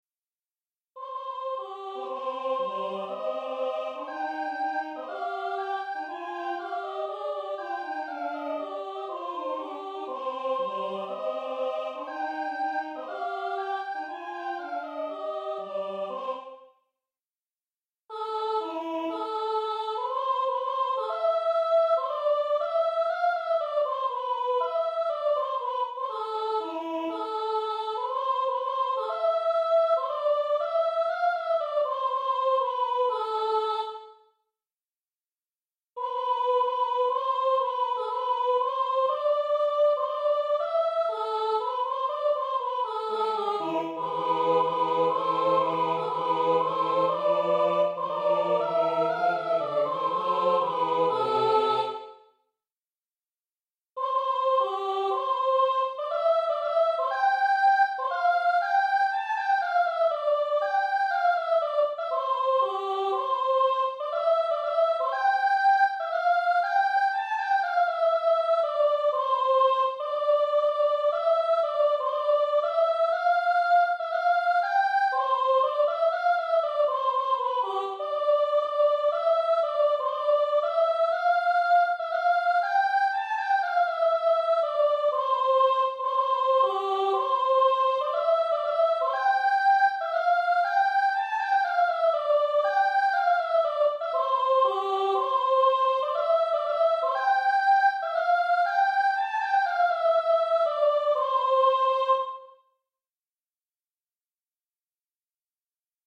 Partitions et enregistrements audio séquenceur du morceau L'Arlésienne - Farandole - Marche des Rois, de Georges Bizet, Classique.